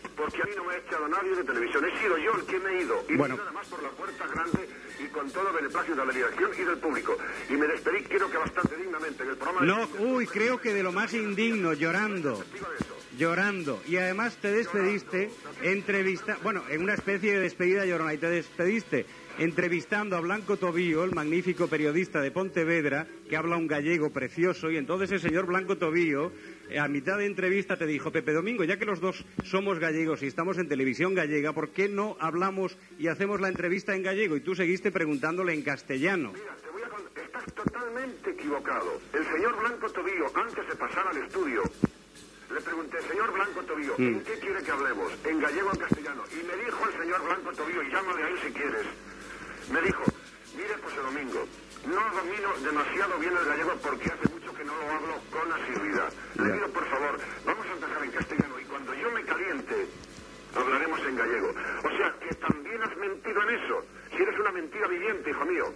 Discussió entre Jesús Mariñas i el presentador Pepe Domingo Castaño que havia acabat la seva feina a Televisió Española
Info-entreteniment
Programa presentat per Luis del Olmo.